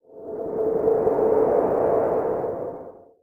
externalriftwind.wav